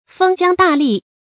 封疆大吏 fēng jiāng dà lì
封疆大吏发音
成语注音 ㄈㄥ ㄐㄧㄤ ㄉㄚˋ ㄌㄧˋ